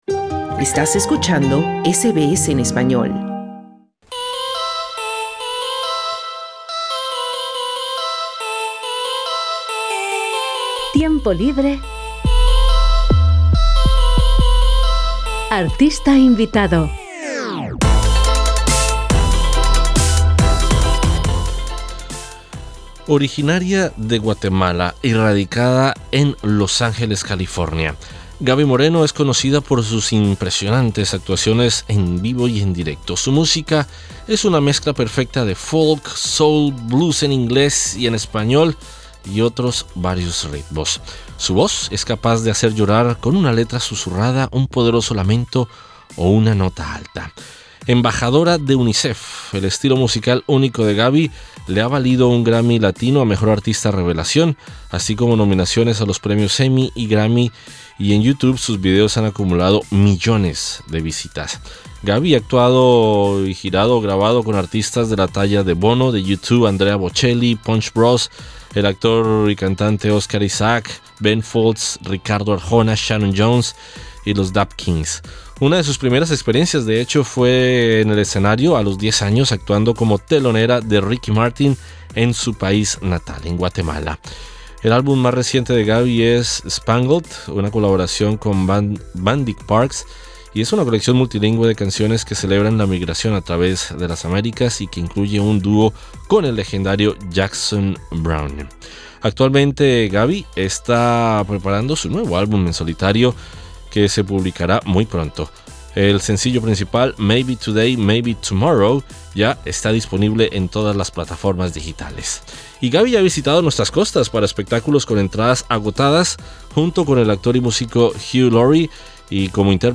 La artista guatemalteca, galardonada con un Grammy y quien apuesta por una música multilingüe y de estilos diversos, actuará en el festival Womadelaide y en conciertos de Sídney, Melbourne y Camberra. Gaby Moreno conversa con SBS Spanish sobre los éxitos de su carrera y sus próximos proyectos.